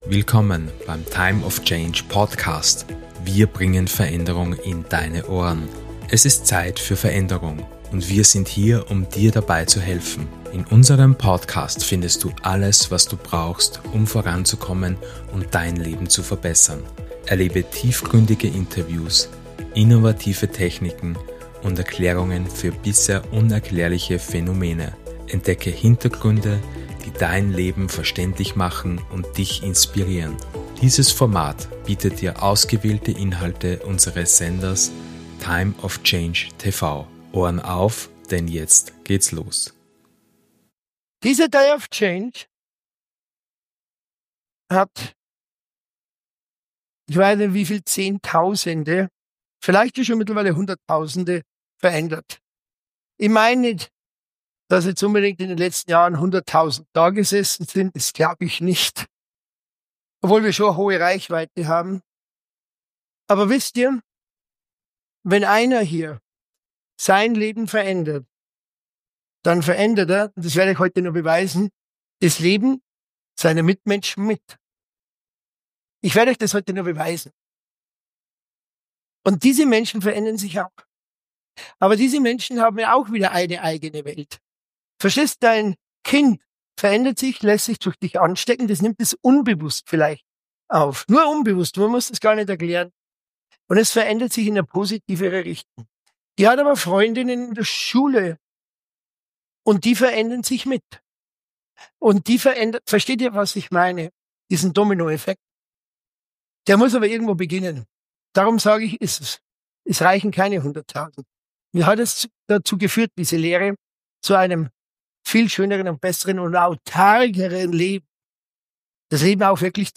Herzlich Willkommen im zweiten Teil des einzigartigen 'Days of Change' Seminarwochenendes! In dieser zweiten Stunde des ersten Seminartages tauchst Du in das faszinierende Thema des ’Gefühlsalchemisten’ und der Gefühlsalchemie ein.